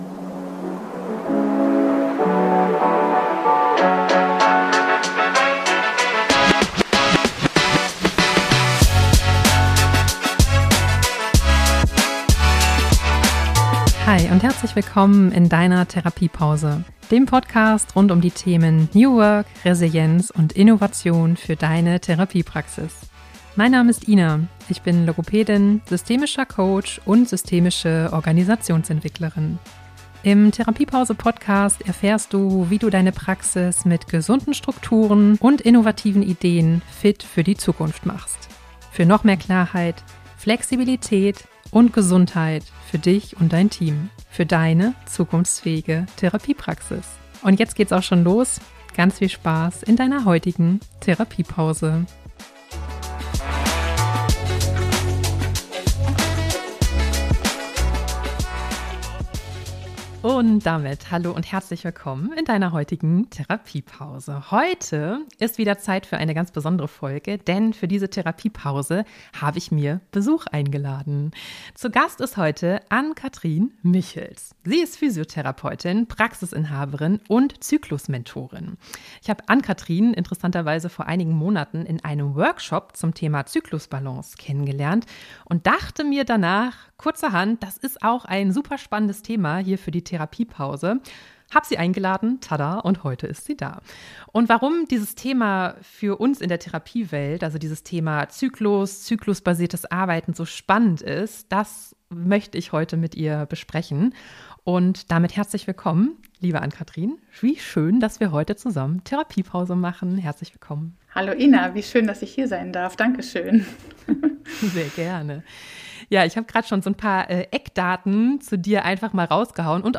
#39 Zyklusbewusst arbeiten - warum eigentlich? Interview